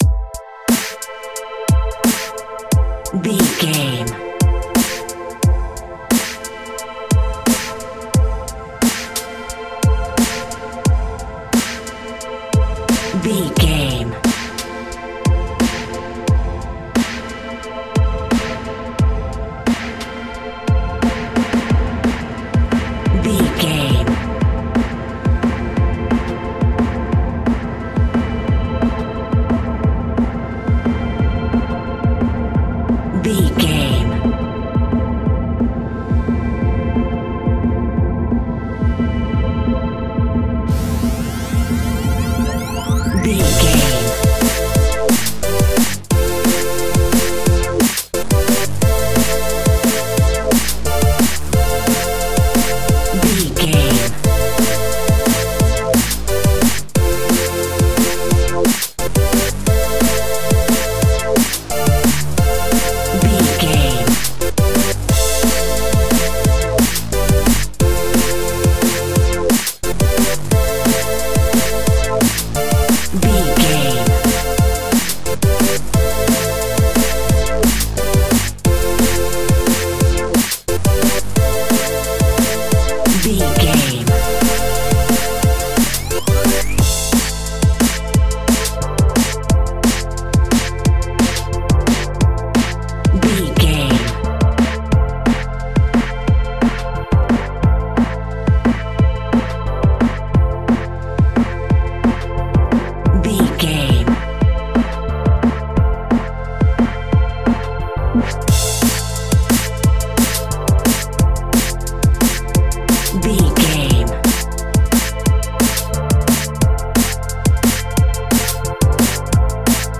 Epic / Action
Fast paced
Aeolian/Minor
aggressive
dark
driving
energetic
futuristic
synthesiser
drum machine
electronic
sub bass
synth leads